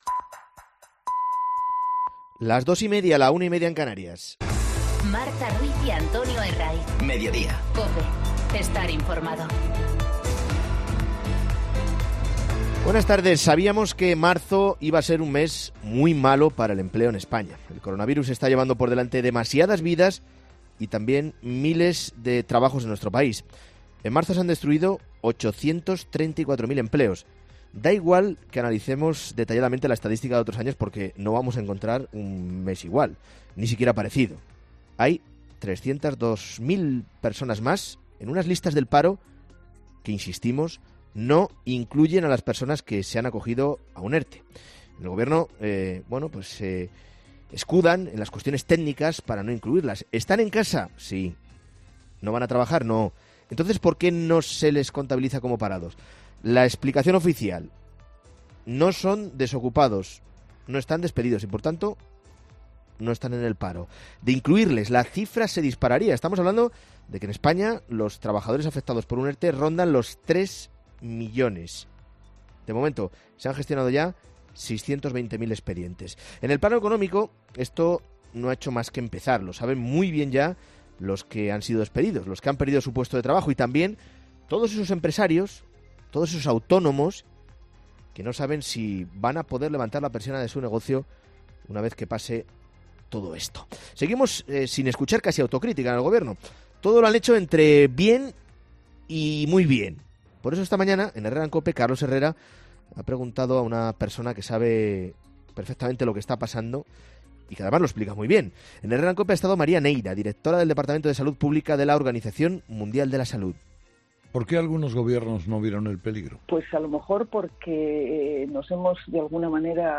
Monólogo